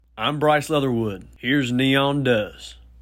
LINER Bryce Leatherwood (Neon Does) 1
LINER-Bryce-Leatherwood-Neon-Does-1.mp3